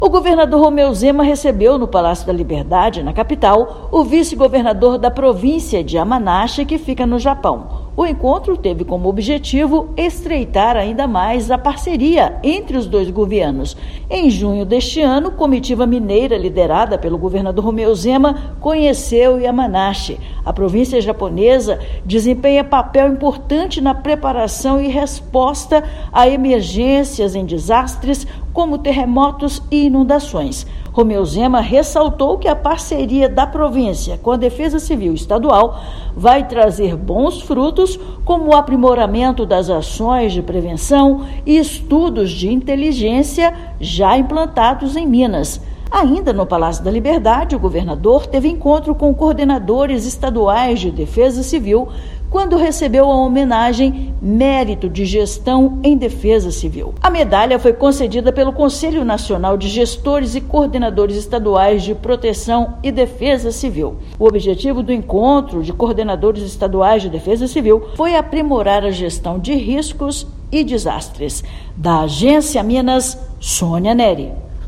Encontro entre governador de Minas Gerais e vice-governador japonês aconteceu no Palácio da Liberdade e mirou em ações de combate a riscos de desastres e intercâmbio de jovens. Ouça matéria de rádio.